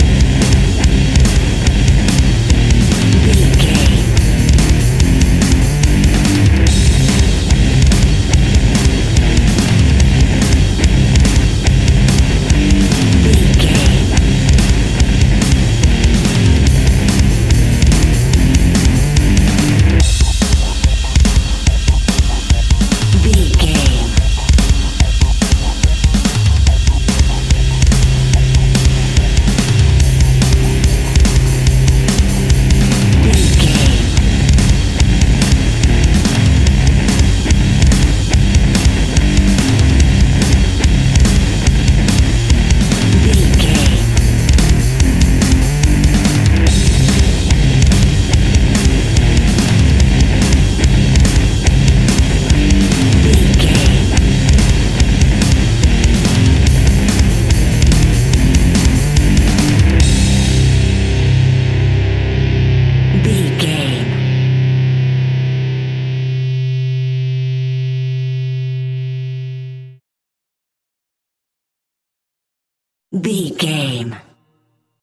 Epic / Action
Fast paced
Aeolian/Minor
hard rock
heavy metal
distortion
instrumentals
Rock Bass
heavy drums
distorted guitars
hammond organ